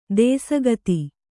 ♪ dēsa gati